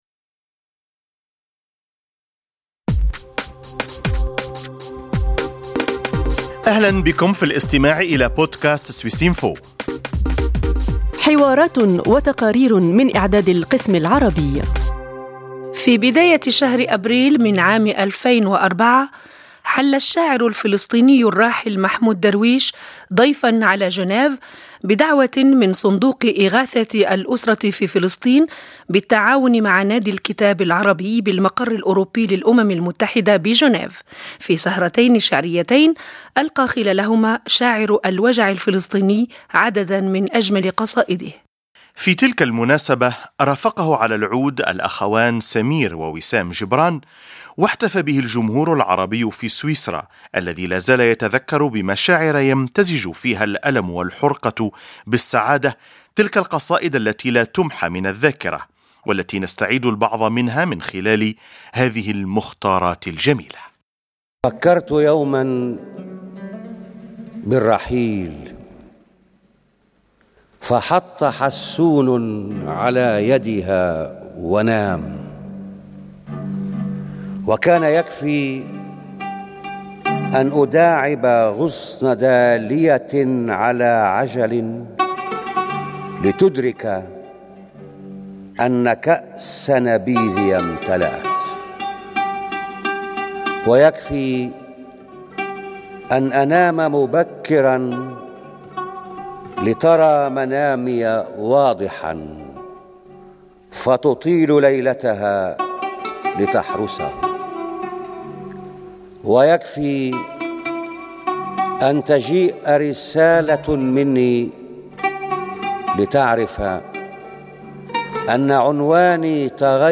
في ربيع 2004، استمع مئات الأشخاص في جنيف للشاعر الفلسطيني الراحل محمود درويش، وهو يُـلقي عددا من أجمل قصائده. الدعوة جاءت من صندوق إغاثة الأسرة في فلسطين الذي تعاون مع نادي الكتاب العربي بالمقر الأوروبي للأمم المتحدة في جنيف في تنظيم سهرتين شعريتين، لا زالتا راسختين في الأذهان.